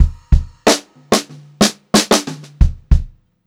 92ST2FILL1-R.wav